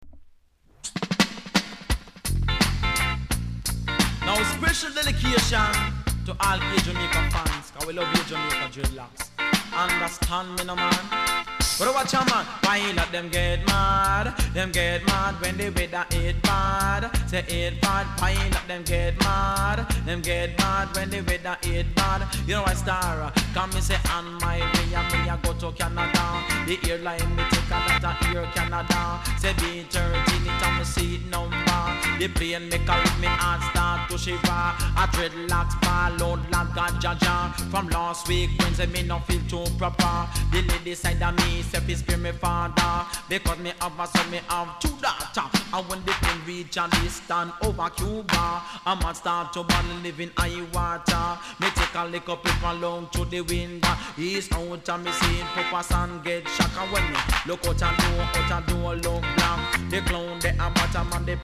RARE DEEJAY!!